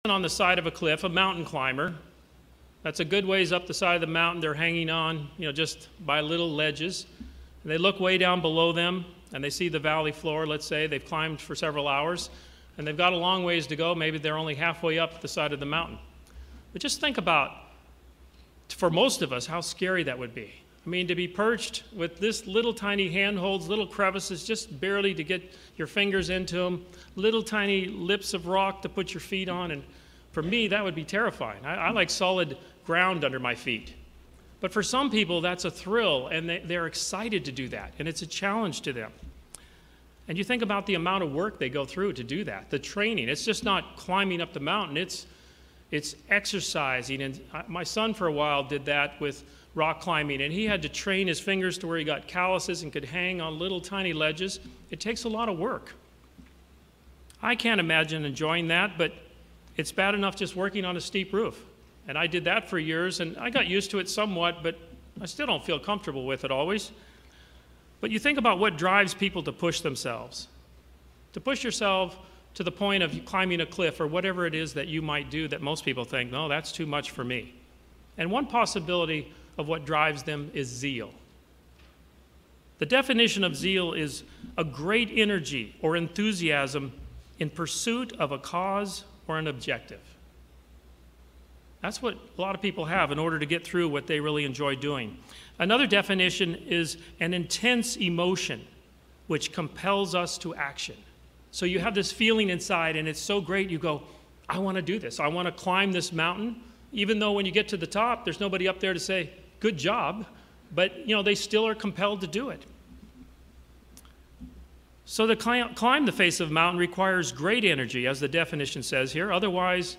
Sermons
Given in Orlando, FL